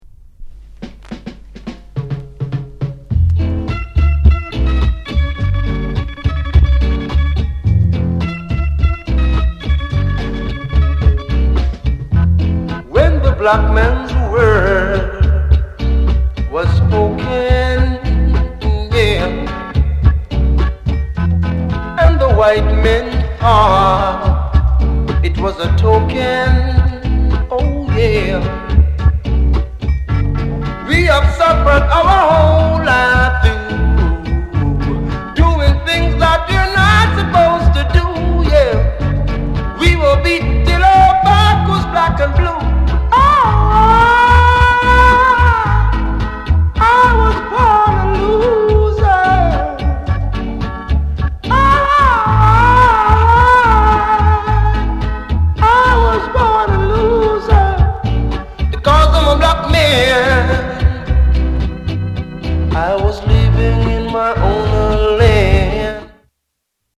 NICE VOCAL